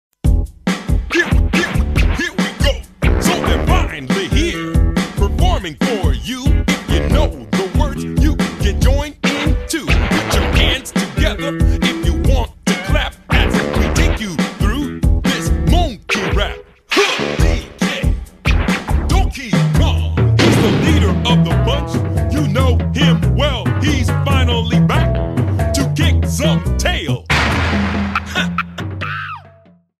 Trimmed to 30 seconds and applied fadeout